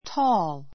tɔ́ːl